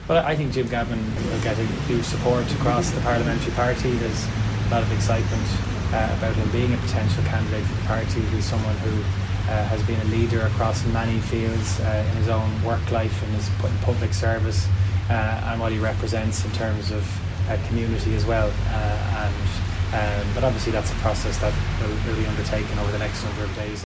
Minister Jack Chambers says there’s a lot of excitement around the party with Jim Gavin’s name in the mix.